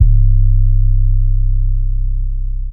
MZ 808 [Metro Bass].wav